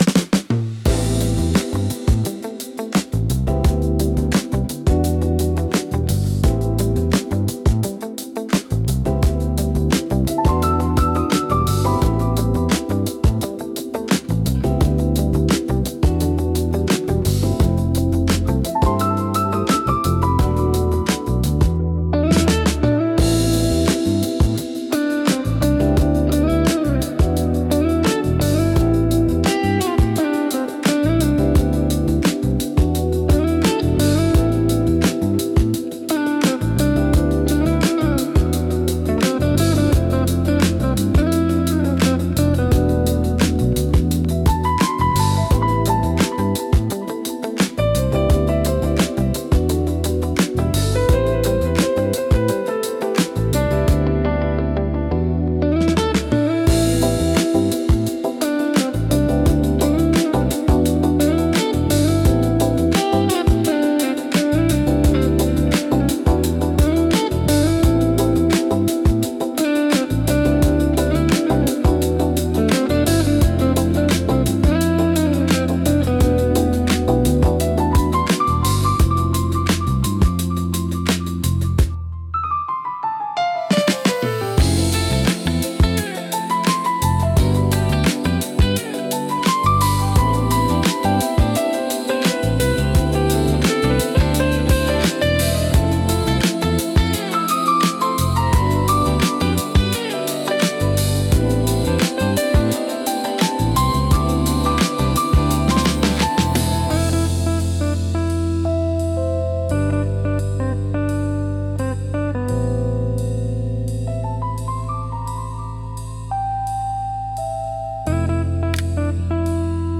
リラックス効果が高く、会話の邪魔をせず心地よい背景音として居心地の良さを高めます。